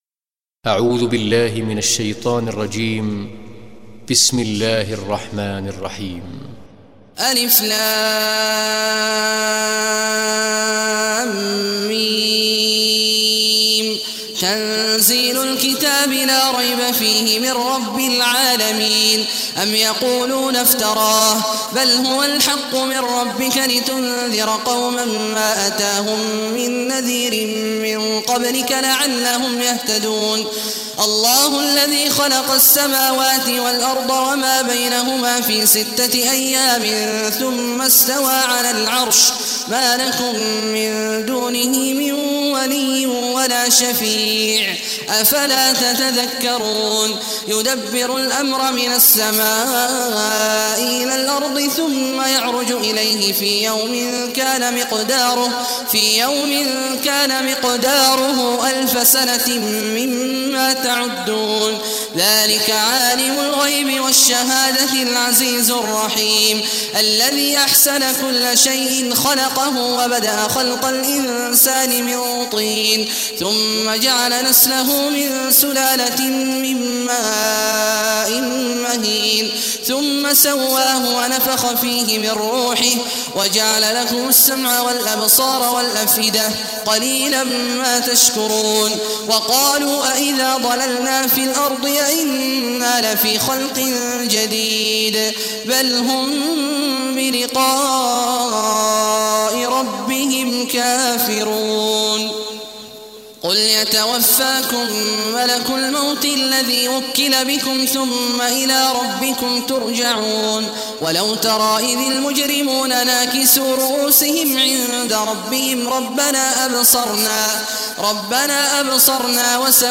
Surah As-Sajdah Recitation by Sheikh Awad Juhany
Surah As-Sajdah, listen or play online mp3 tilawat / recitation in Arabic in the beautiful voice of Sheikh Abdullah Awad al Juhany.